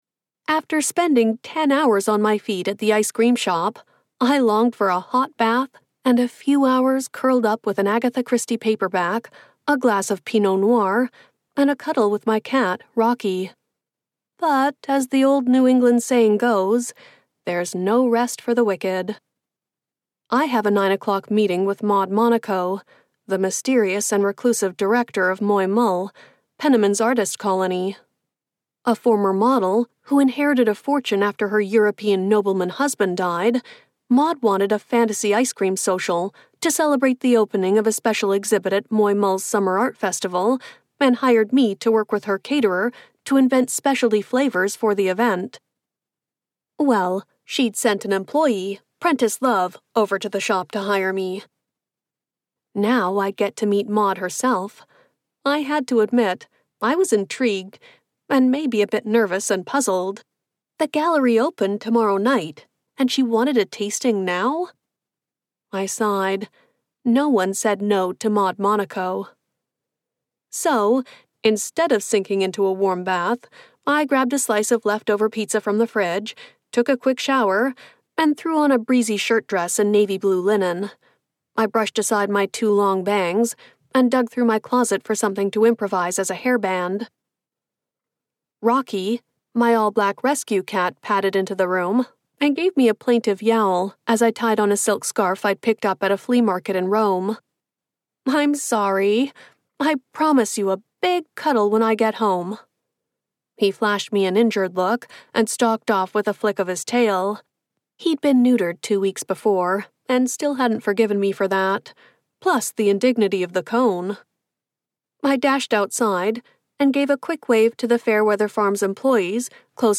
Mint Chocolate Murder - An Ice Cream Shop Mystery, Book Two - Vibrance Press Audiobooks - Vibrance Press Audiobooks